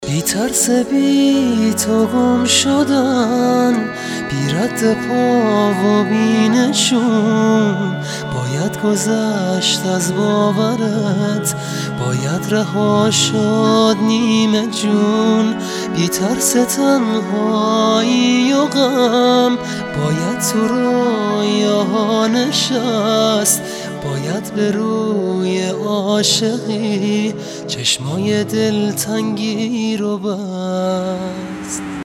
رینگتون باکلام بسیار زیبا و رمانتیک